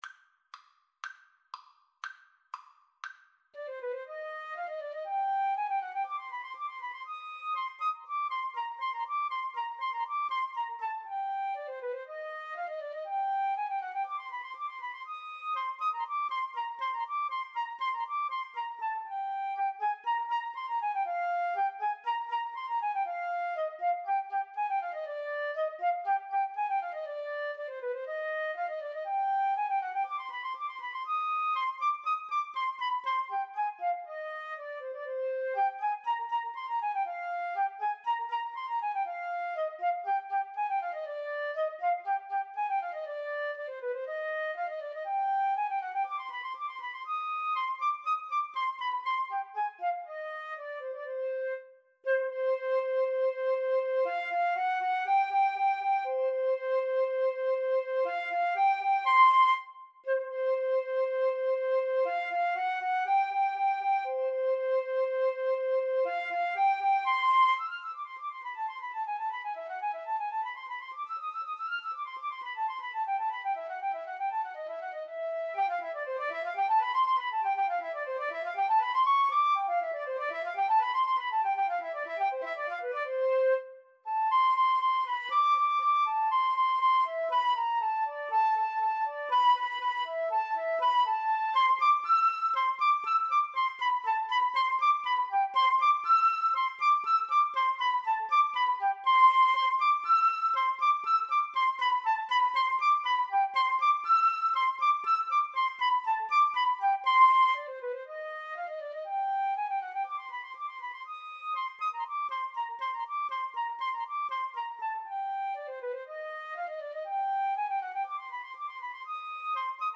Free Sheet music for Flute-Saxophone Duet
C minor (Sounding Pitch) (View more C minor Music for Flute-Saxophone Duet )
Allegro con brio (View more music marked Allegro)
Classical (View more Classical Flute-Saxophone Duet Music)